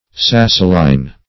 Search Result for " sassoline" : The Collaborative International Dictionary of English v.0.48: Sassolin \Sas"so*lin\, Sassoline \Sas"so*line\, n. [From Sasso, a town in Italy: cf. F. sassolin.]
sassoline.mp3